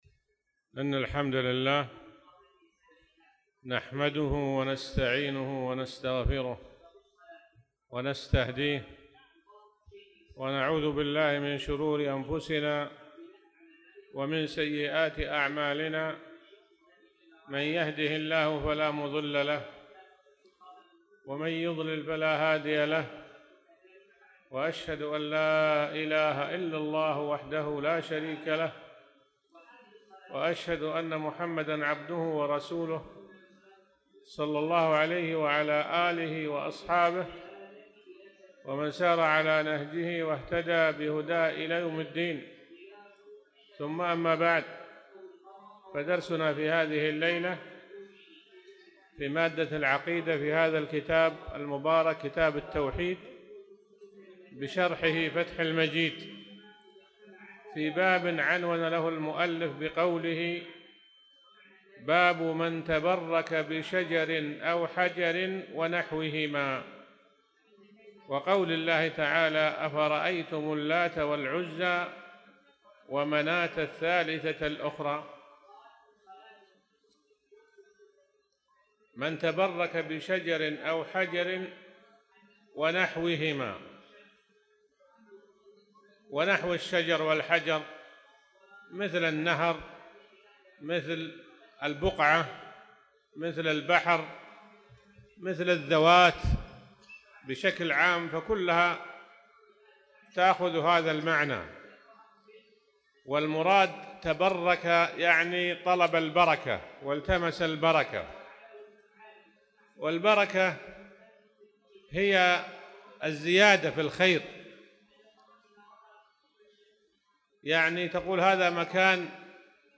تاريخ النشر ٢٨ شوال ١٤٣٧ هـ المكان: المسجد الحرام الشيخ